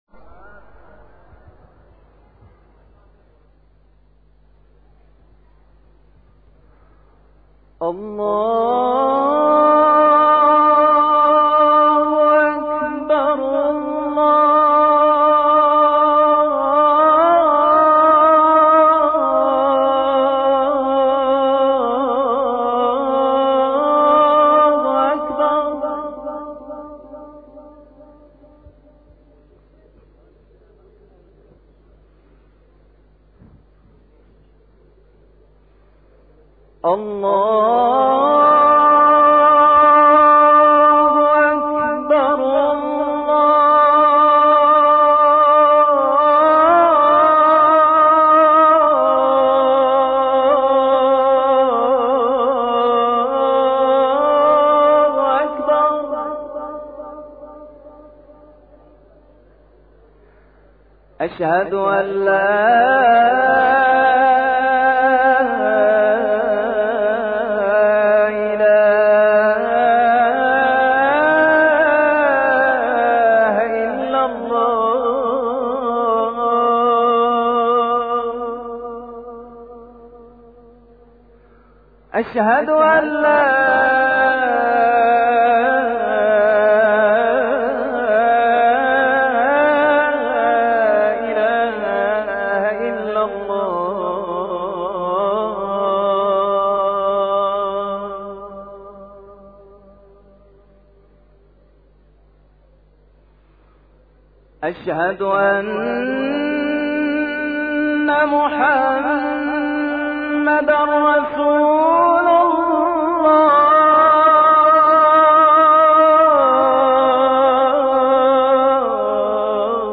اذان انتظار
AZANE-ENTEZAR.mp3